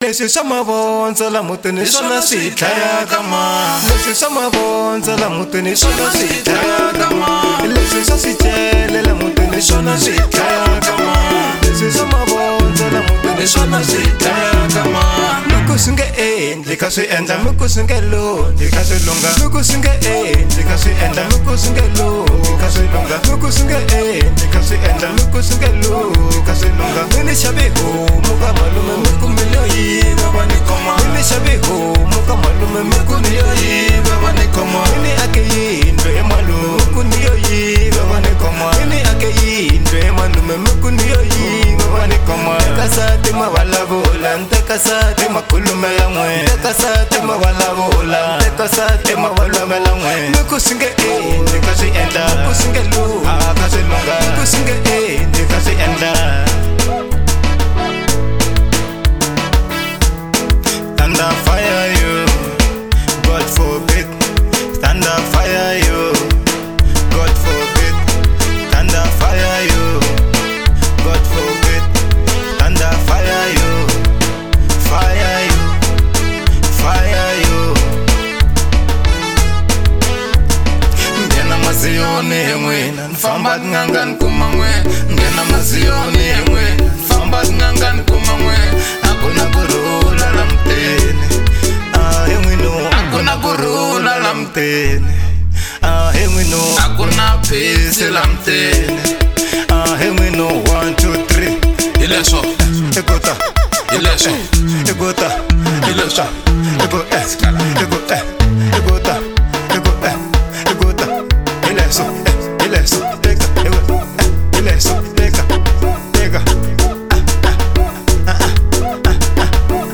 03:52 Genre : Marrabenta Size